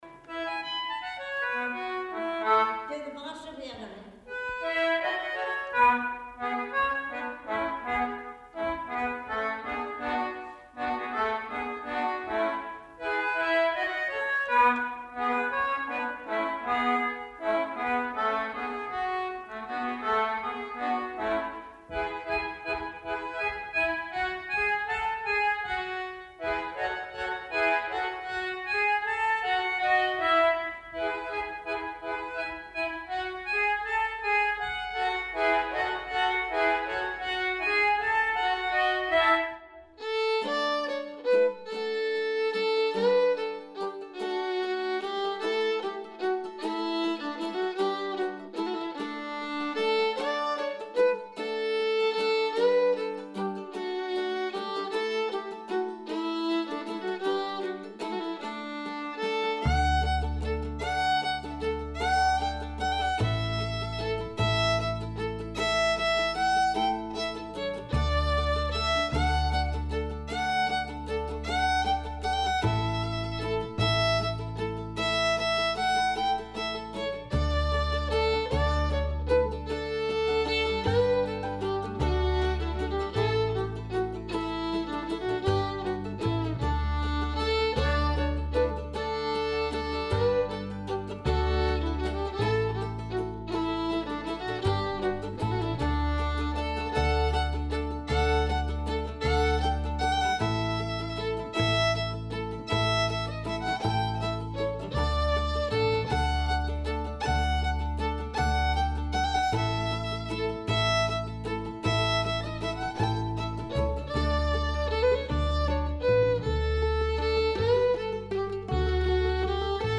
The Anglo-German concertina made a large impact on Australia, especially with its repertoire of folk music.
an Anglo player was 92 years old when I recorded her version of the Varsoviena
Stanley Concertina